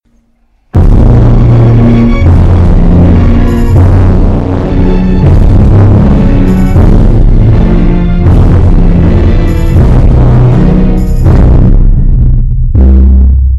Kira yoshikage bass boosted
kira-yoshikage-bass-boosted.mp3